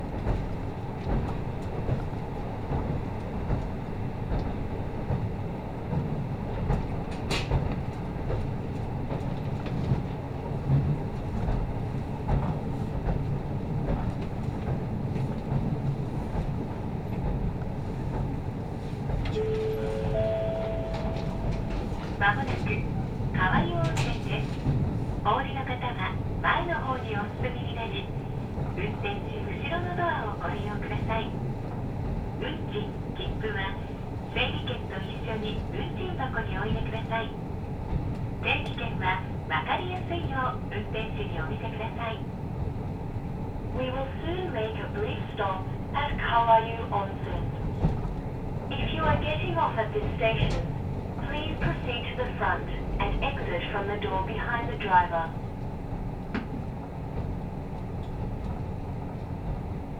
中斜里駅を出発したキハ54系の単行列車は、白く染まった農村地帯をゆっくりと進んでいく。